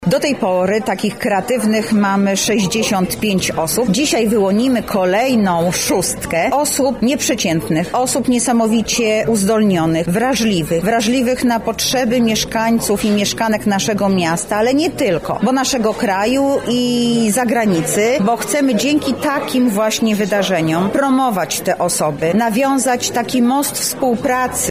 O szczegółach mówi Zastępca Prezydenta Lublina ds. Społecznych, Anna Augustyniak: